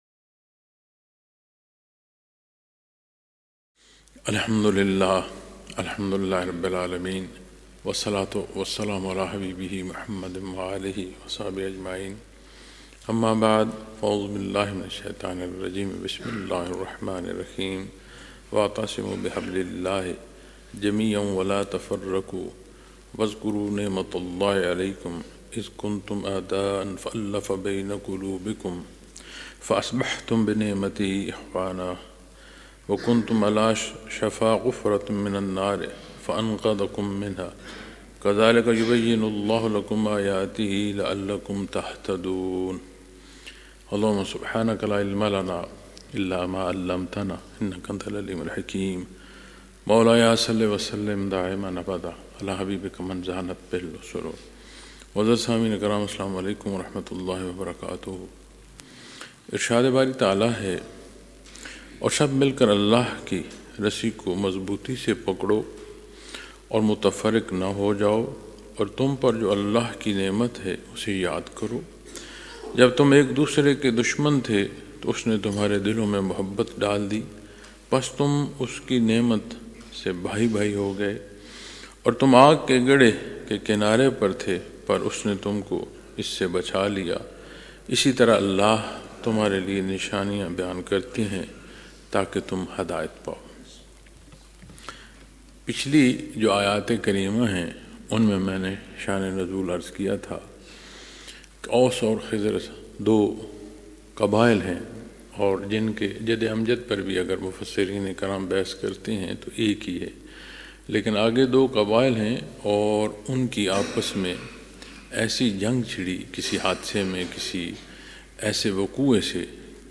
Lectures in Munara, Chakwal, Pakistan on December 26,2025